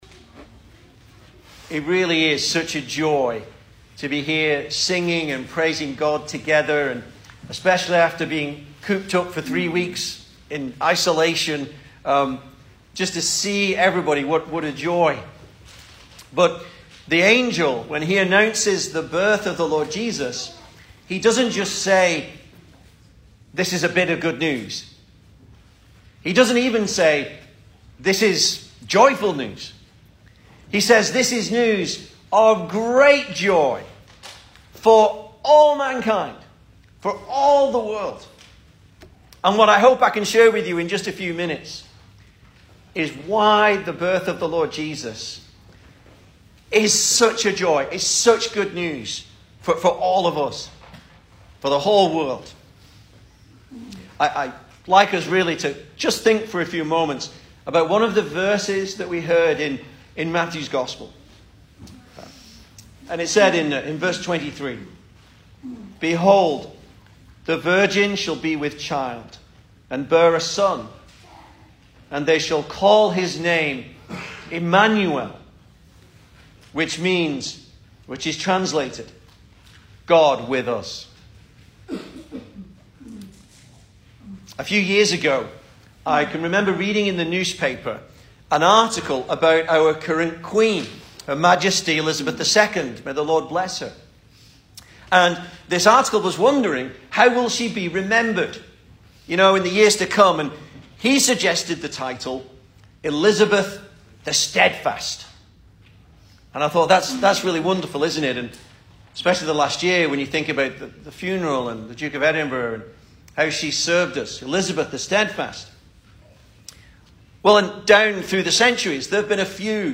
Service Type: Sunday Evening
Christmas Sermons